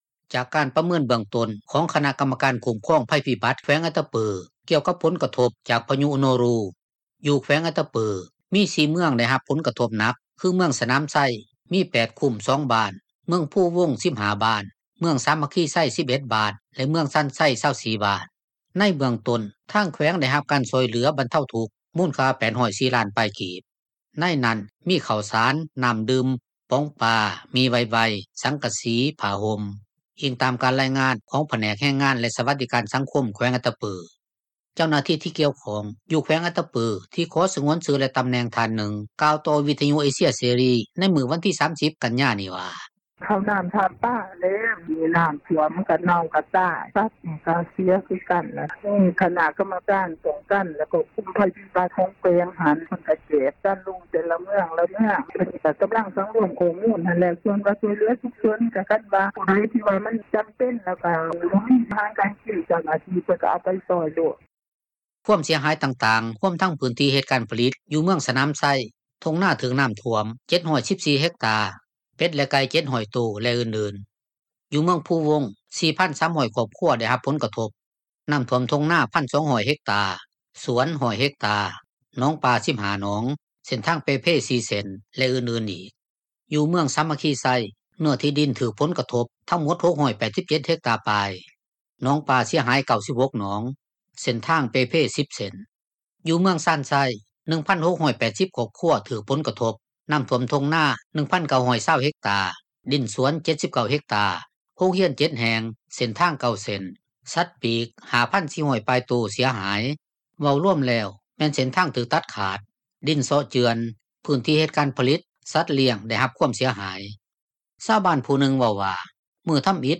ເຈົ້າໜ້າທີ່ ທີ່ກ່ຽວຂ້ອງ ຢູ່ແຂວງອັດຕະປື ທີ່ຂໍສງວນຊື່ ແລະຕໍາແໜ່ງ ທ່ານນຶ່ງ ກ່າວຕໍ່ວິທຍຸ ເອເຊັຽເສຣີ ໃນມື້ວັນທີ 30 ກັນຍານີ້ວ່າ: